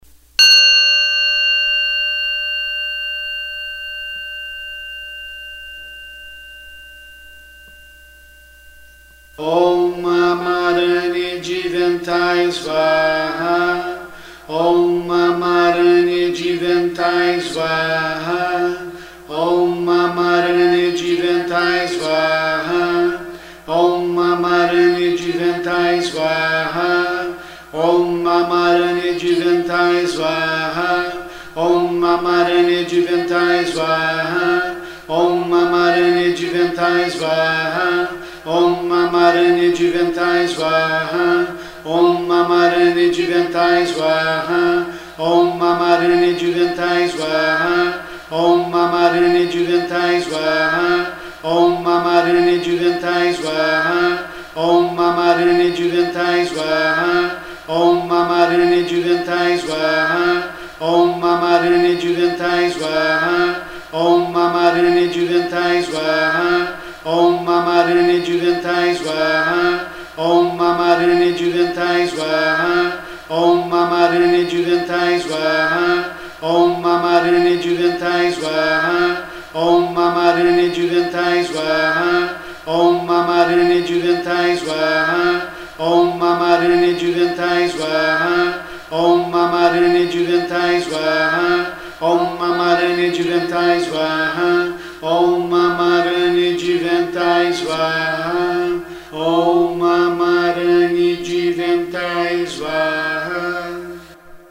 MANTRAS PARA O SENHOR GAUTAMA BUDA
OM AMA-RÃ-NI DI-VEN-TA-IE SVAARRA